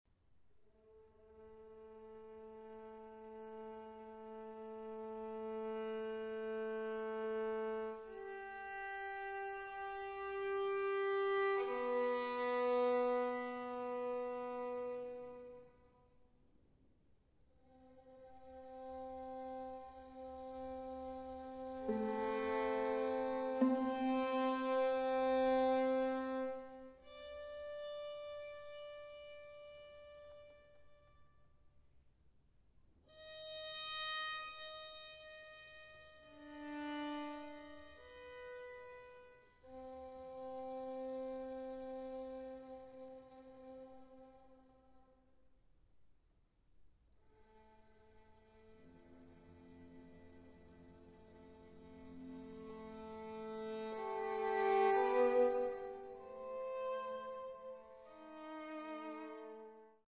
Cello
Viola
Violin